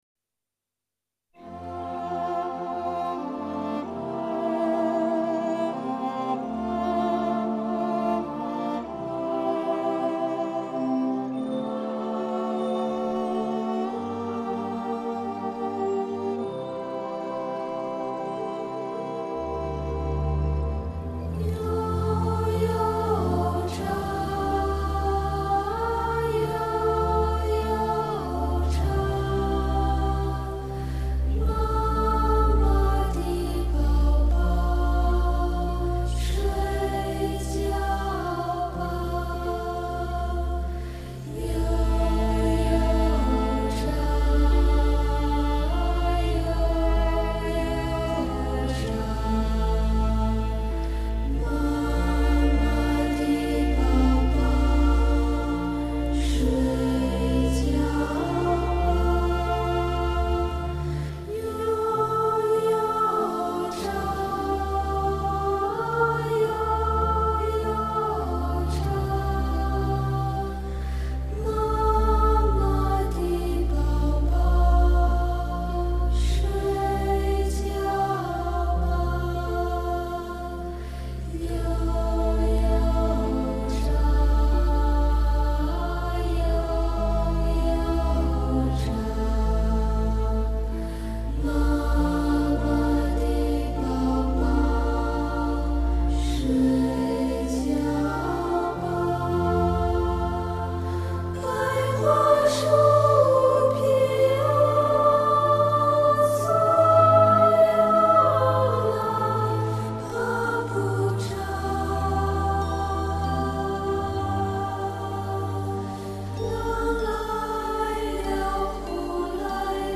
童声合唱是一种广受欢迎的形式，它包含错落的声部构成
国内少有的专业童声合唱团体，团员均属于“一时之选”